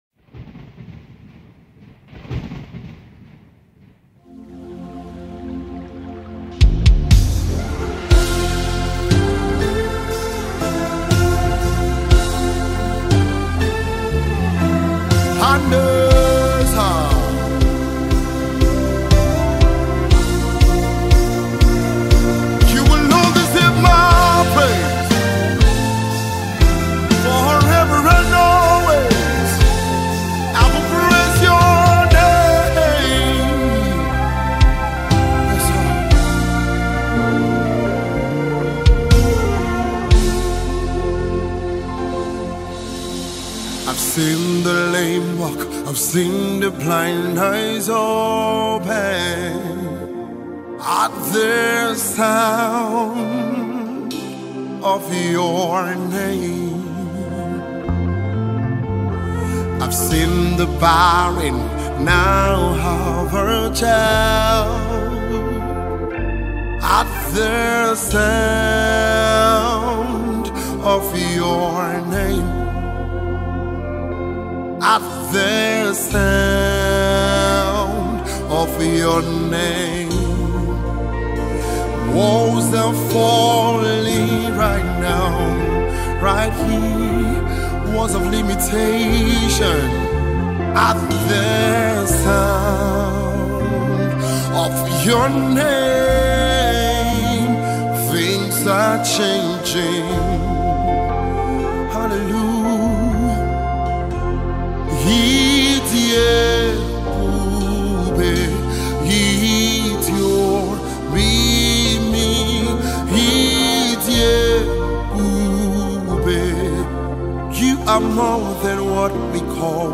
Gospel singer
worship song